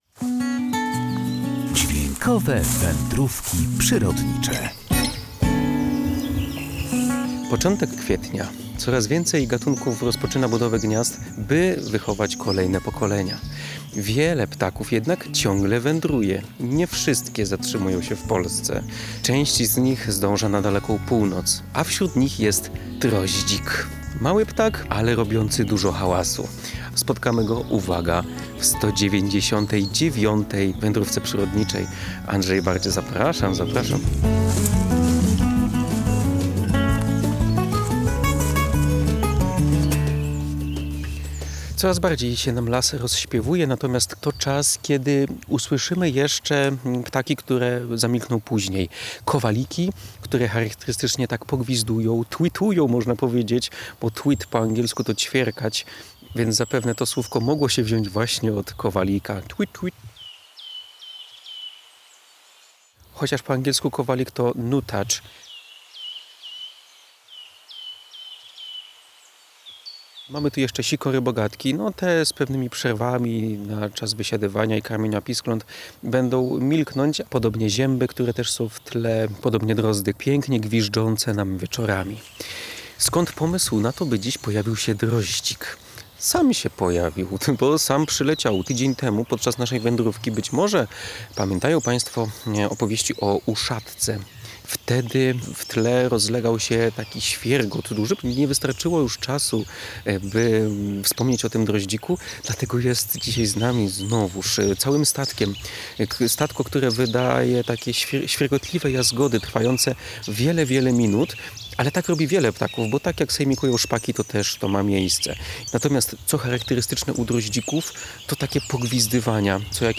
Wśród nich jest droździk – mały ptak, ale robiący dużo hałasu.